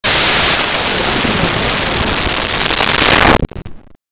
Alien Woosh 02
Alien Woosh 02.wav